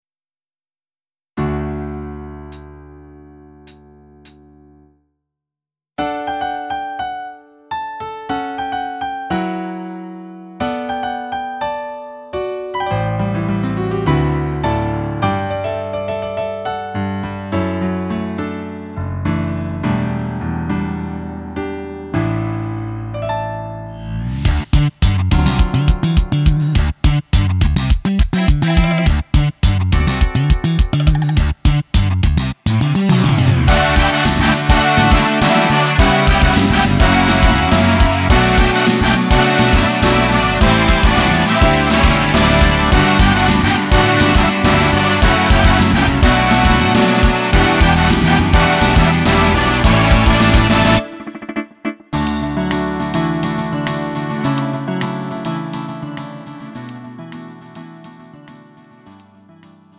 음정 원키 3:16
장르 가요 구분